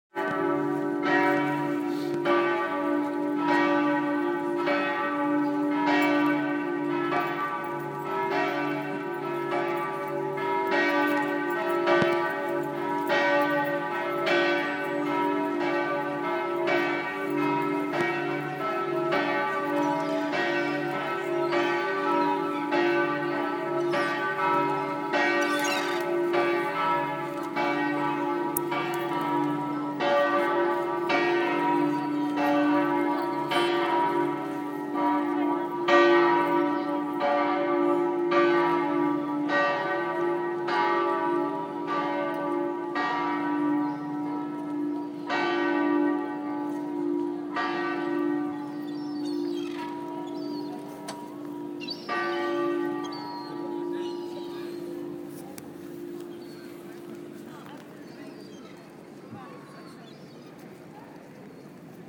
The Bells of Piazza San Marco